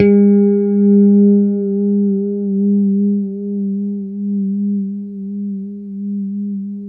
A3 PICKHRM1A.wav